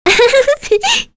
Kirze-Vox_Happy2.wav